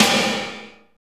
51.08 SNR.wav